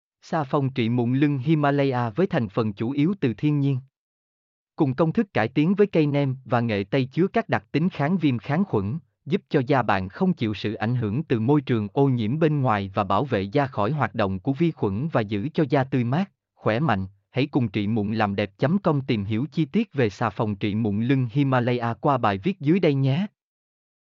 mp3-output-ttsfreedotcom-1-2.mp3